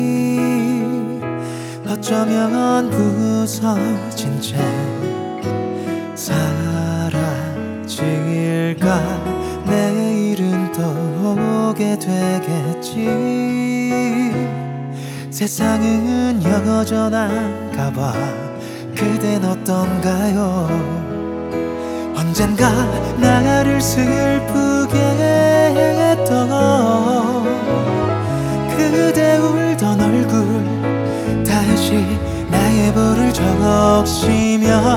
Жанр: Поп музыка / R&B / Соул
R&B, Soul, Pop, K-Pop